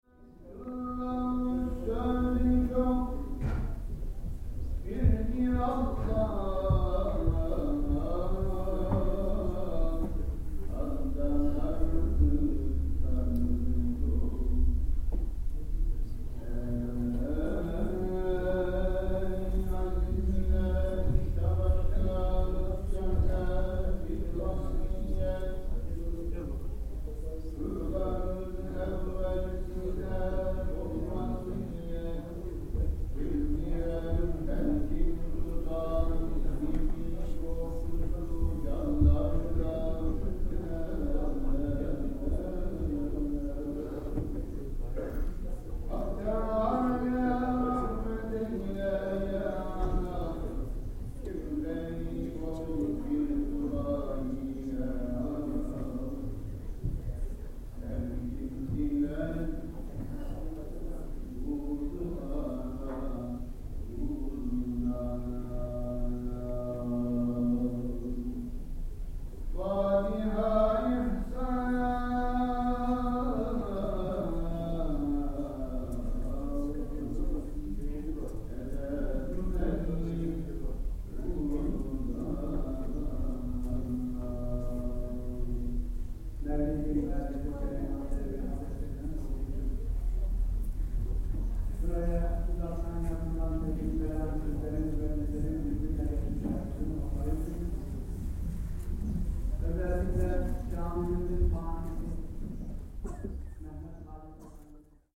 The lady on the recording ordered a portion of one kilo. Listen to the delicious cutting of the börek and smooth service: